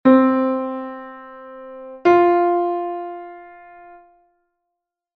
Listening to ascending and descending intervals